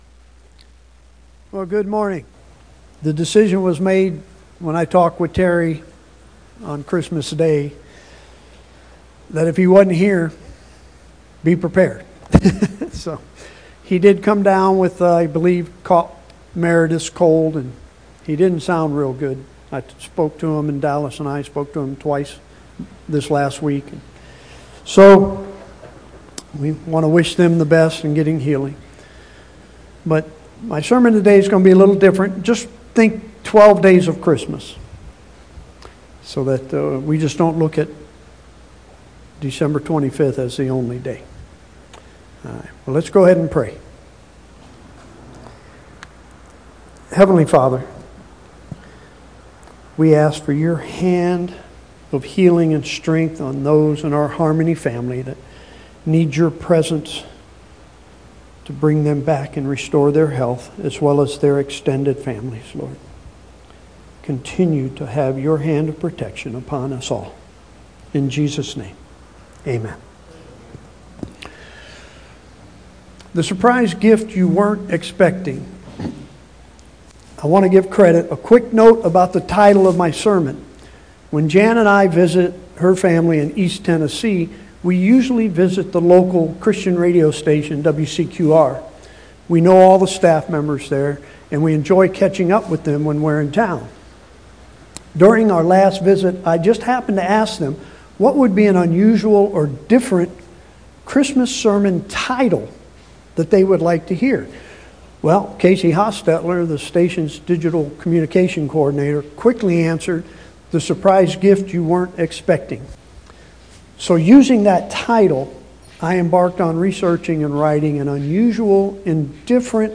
Weekly sermons from Harmony Community Church in Byron, Georgia.
1229Sermon.mp3